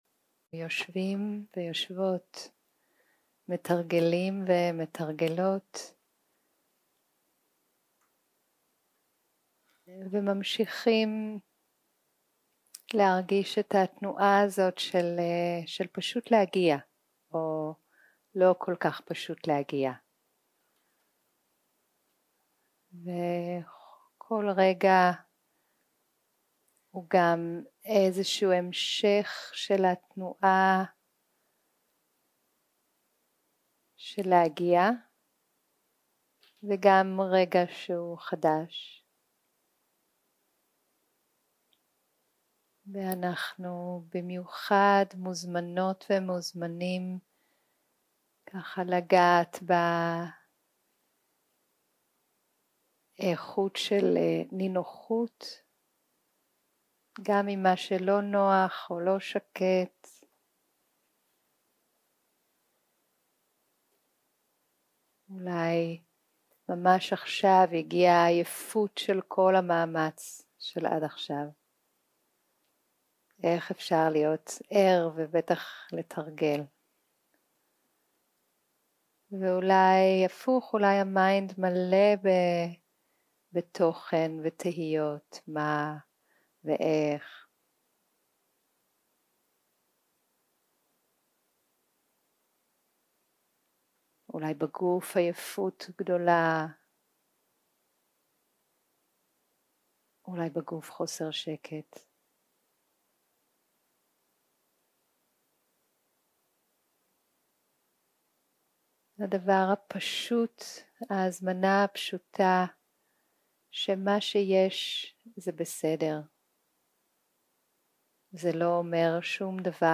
יום 1 - הקלטה 1 - ערב - מדיטציה מונחית - ביסוס תשומת לב לגוף 0:00 0:00 סוג ההקלטה: Dharma type: Guided meditation שפת ההקלטה: Dharma talk language: Hebrew